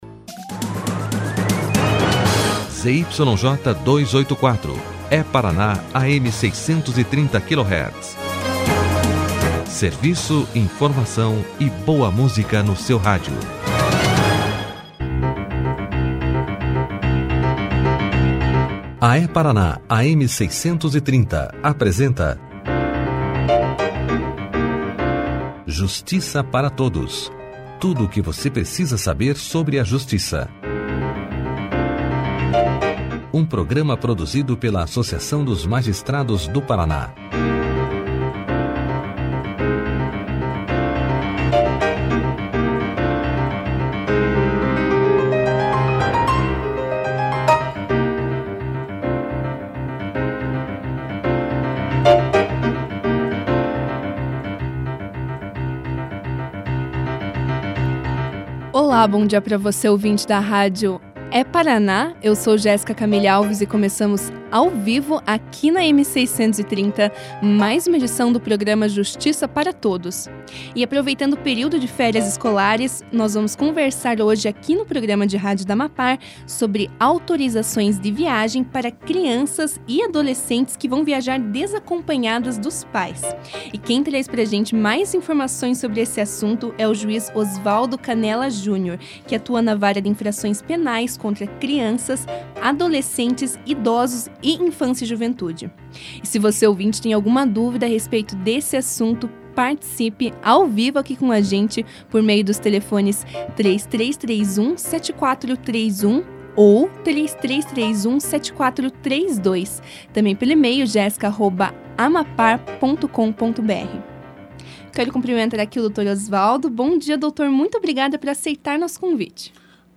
Juiz Osvaldo Canela Júnior fala sobre as autorizações de viagens para crianças e adolescentes no Justiça Para Todos
No programa Justiça Para Todos dessa terça-feira (12), o juiz Osvaldo Canela Júnior levou aos ouvintes da rádio É-Paraná, AM630, mais informações sobre as autorizações de viagens para crianças e adolescentes, que vão viajar desacompanhados dos pais. O magistrado falou sobre de que maneira funciona a autorização para menores em viagens nacionais e internacionais e qual a importância da mesma, além de outros assuntos ligados ao tema.
Clique aqui e ouça a entrevista do magistrado Osvaldo Canela Júnior sobre as autorizações de viagem para crianças e adolescentes na íntegra.